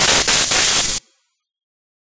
Leakage.ogg